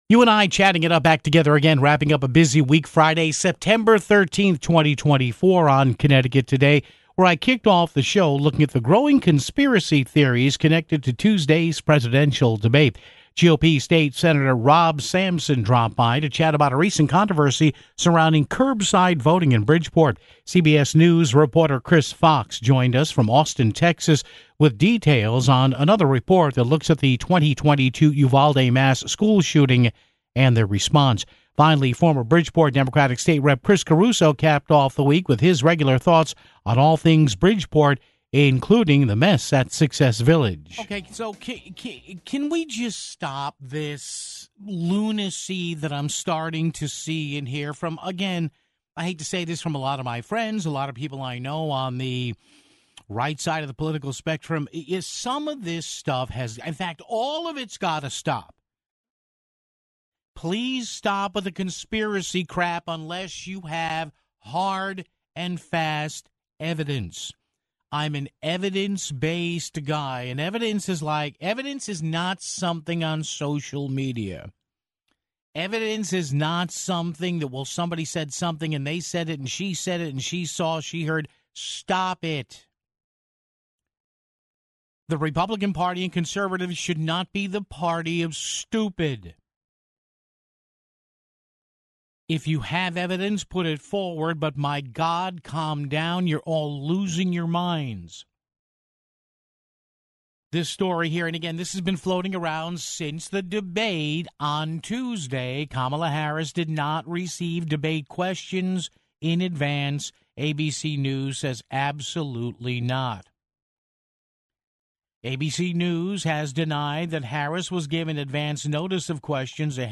GOP State Sen. Rob Sampson dropped by to chat about a recent controversy surrounding "curbside voting" in Bridgeport (07:24).